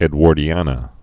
(ĕd-wôrdē-ănə, -änə, -wär-)